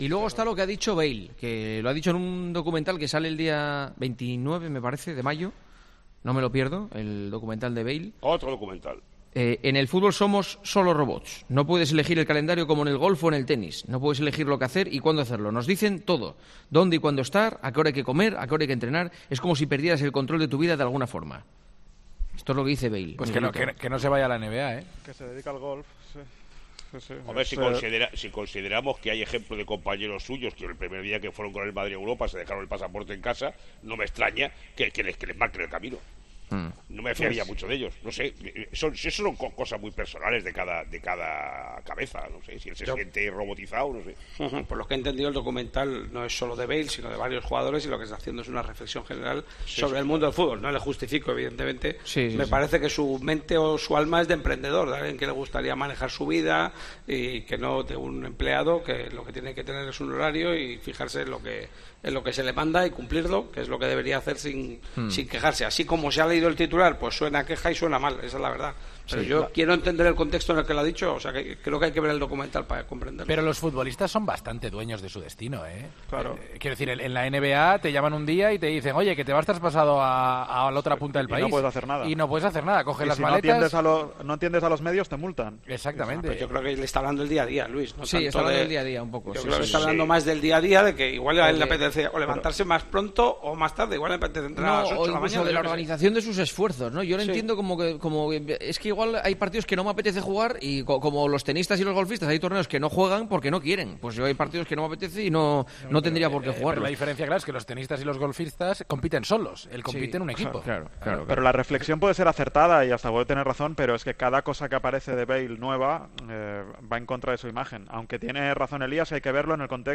En el tiempo de tertulia de 'El partidazo de COPE'